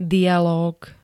dialóg [vysl. dya-], -u m. rozhovor dvoch osôb; div. dramatický d. rozhovor dvoch hercov v divadelnej hre;
Zvukové nahrávky niektorých slov